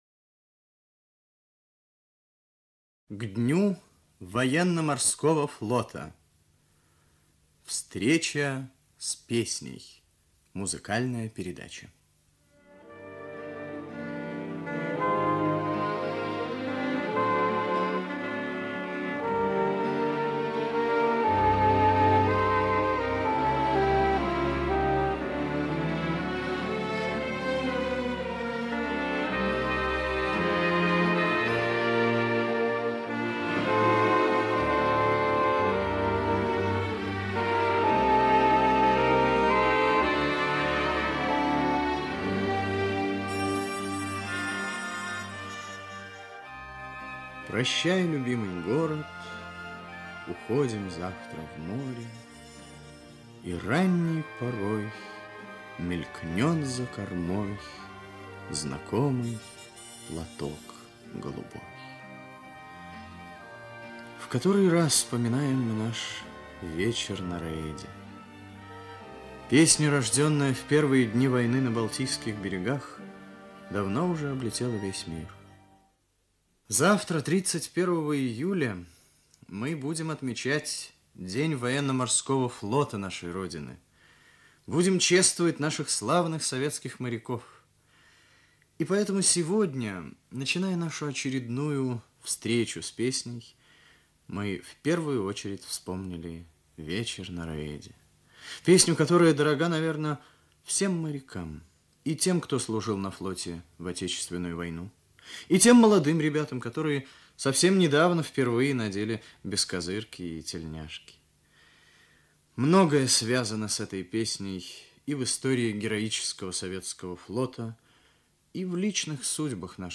Ведущий передачи Виктор Татарский рассказывает о знаменитых героях - моряках, участниках Великой Отечественной войны.